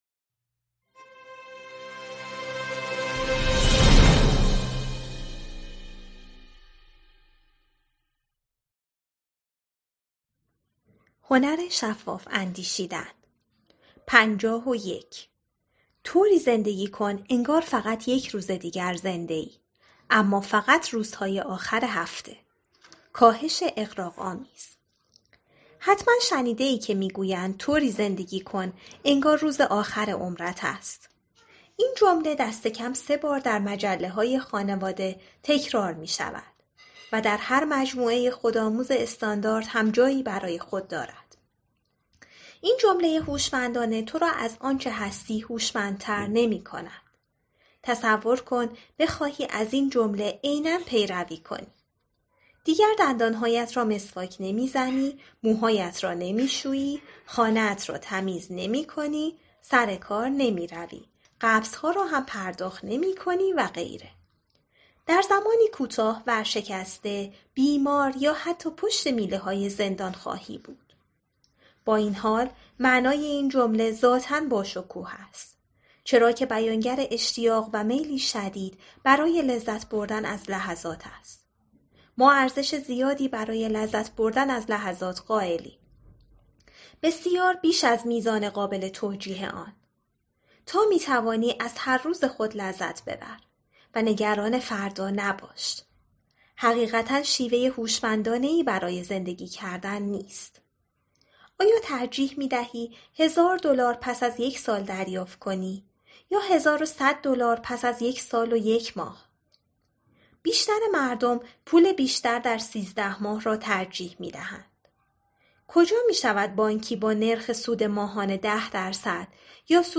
دانلود کتاب صوتی "هنر شفاف اندیشیدن"